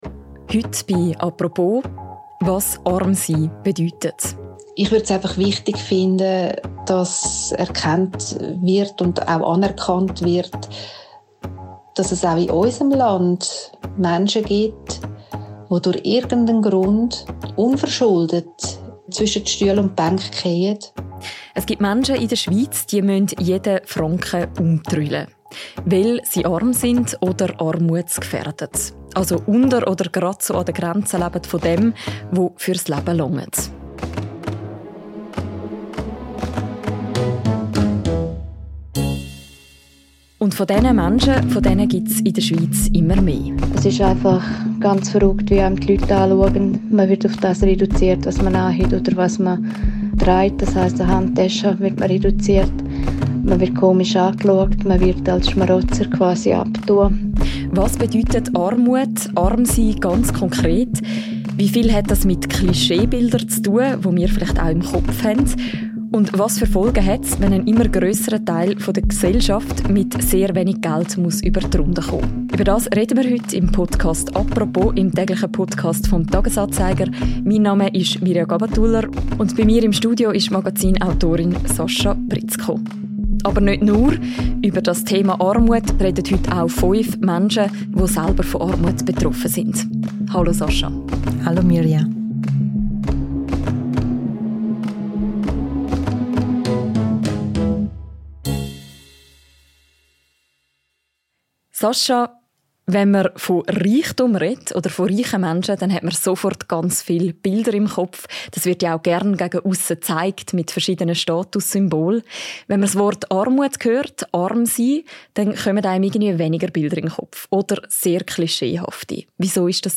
Und fünf Betroffene kommen selbst zu Wort. Was bedeutet Armut für sie?